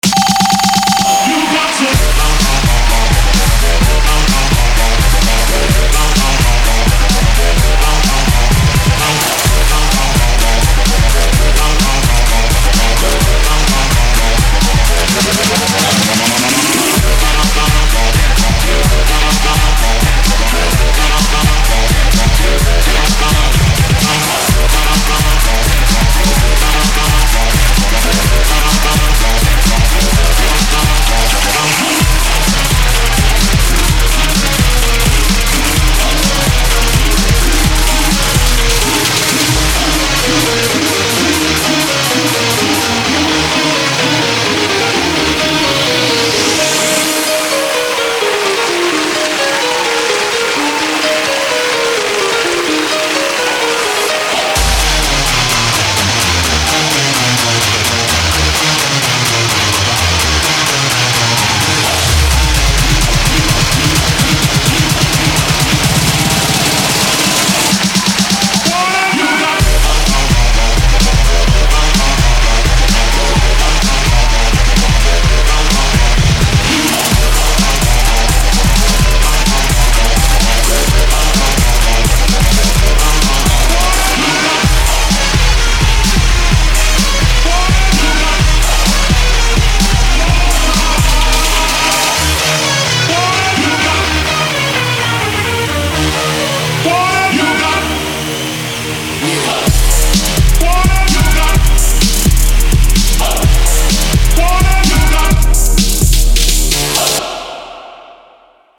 Game Show Bap(3)
Category: Sound FX   Right: Personal